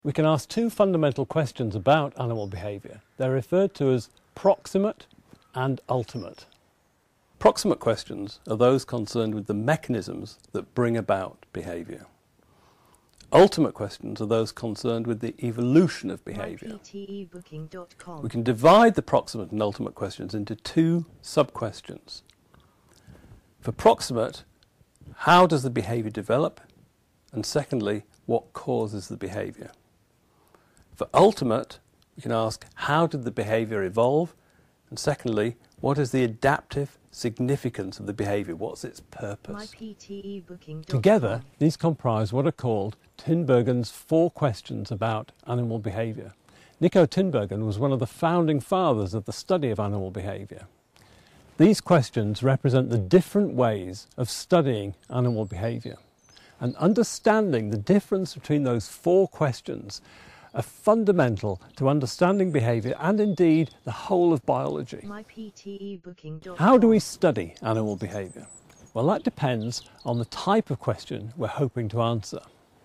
You will hear an Interview/Lecture.